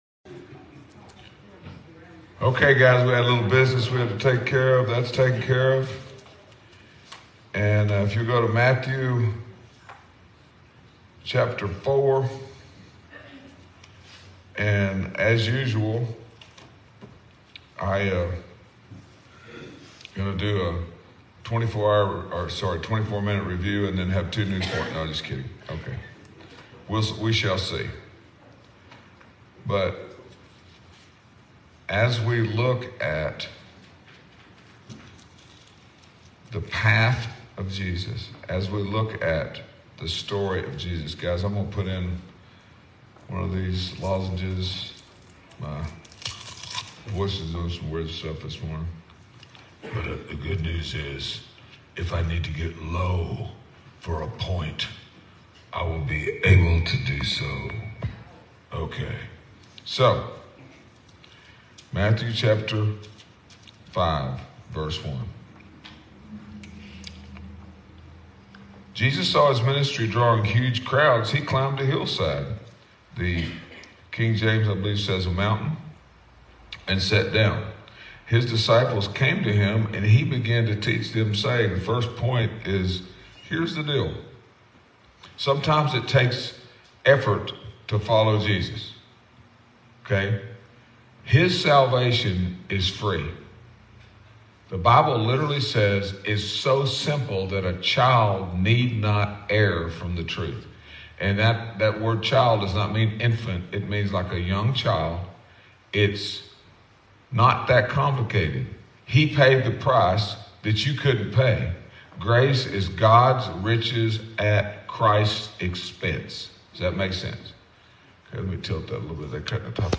Sunday Sermon 9-11-23